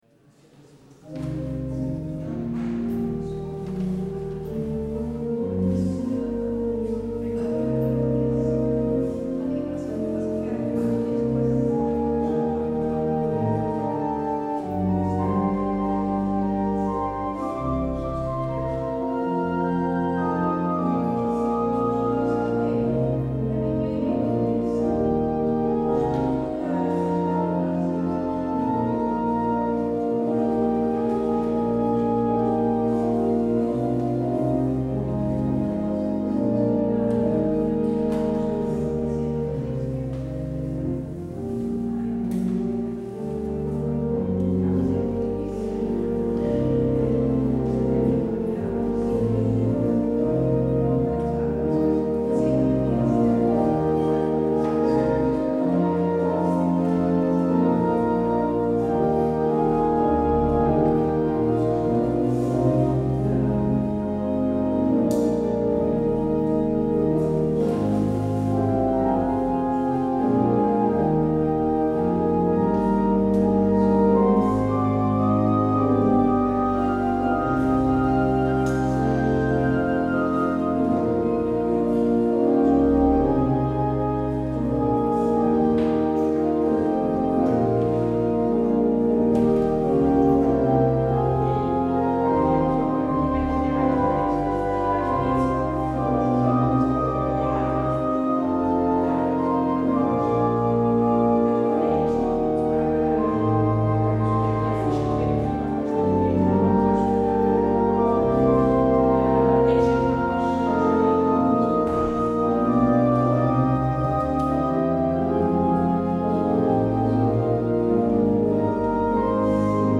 Het openingslied is: Psalm 89: 1 en 8.
Het slotlied is: NLB 990: 1, 2, 3 en 6.